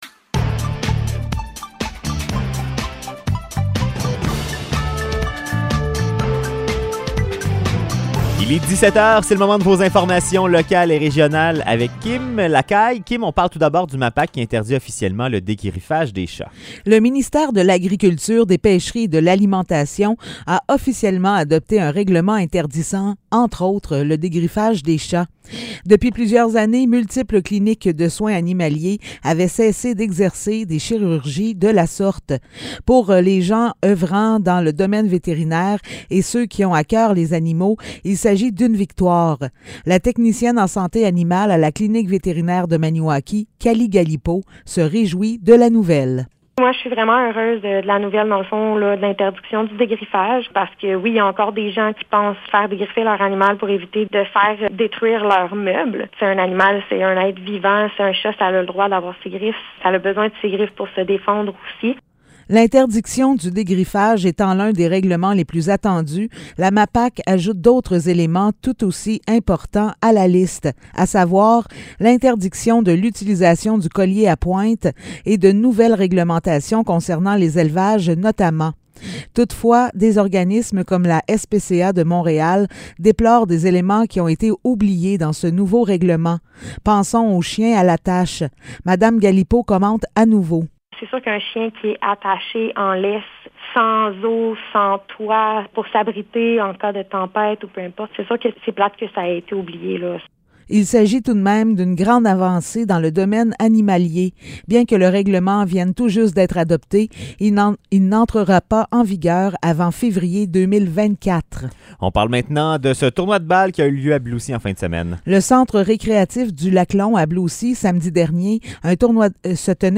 Nouvelles locales – 15 août 2022 – 17 h